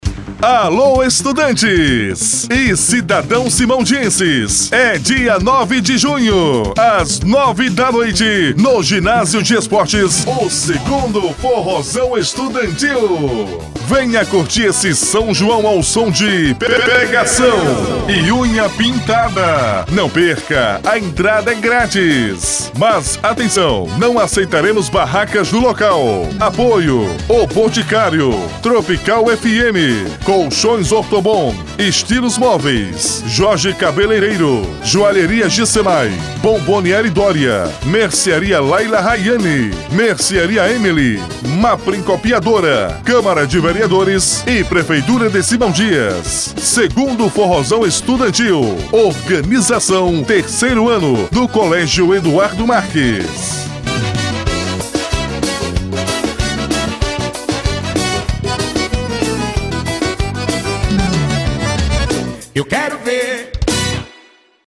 FORRÓ DO COLÉGIO EDUARDO MARQUES